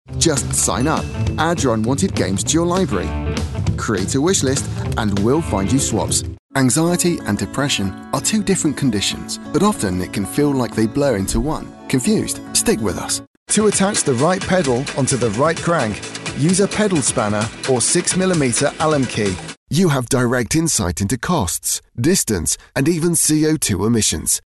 Anglais (Britannique)
Naturelle, Cool, Accessible, Distinctive, Chaude